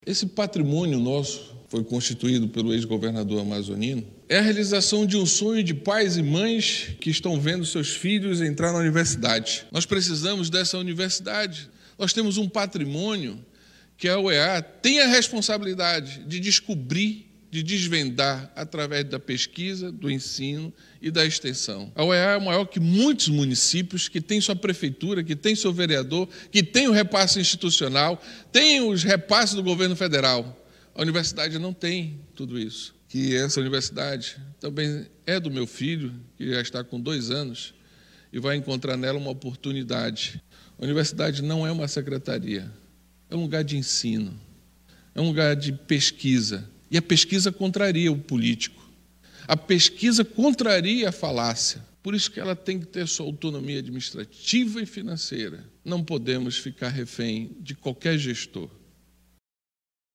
A sessão especial foi realizada no plenário Ruy Araújo da Assembleia Legislativa do Amazonas (Aleam), tendo como presidente da sessão o deputado Dermilson Chagas.